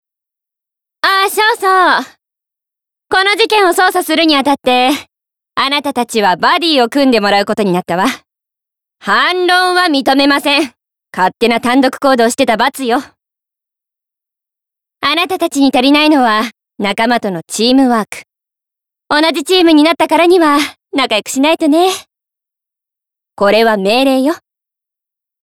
ボイスサンプル
セリフ４